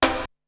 prcTTE44012tom.wav